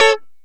Lng Gtr Chik Min 02-F#2.wav